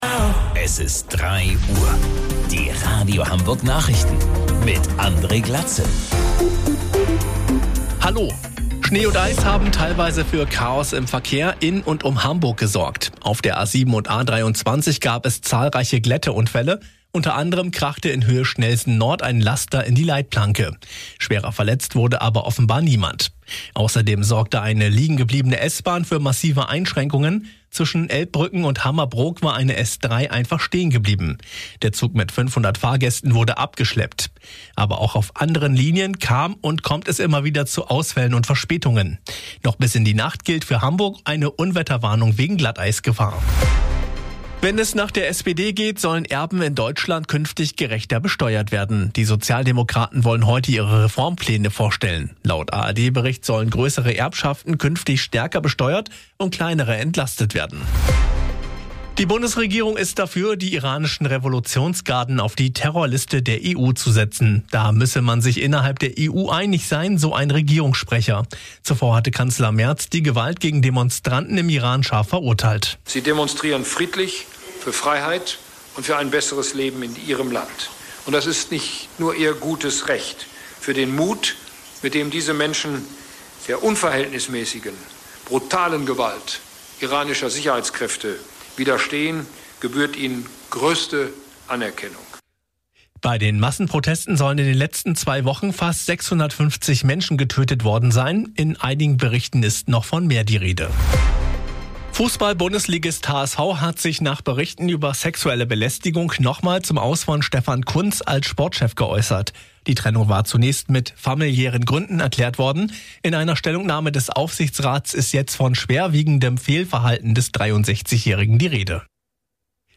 Radio Hamburg Nachrichten vom 13.01.2026 um 03 Uhr